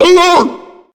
sucker_growl_0.ogg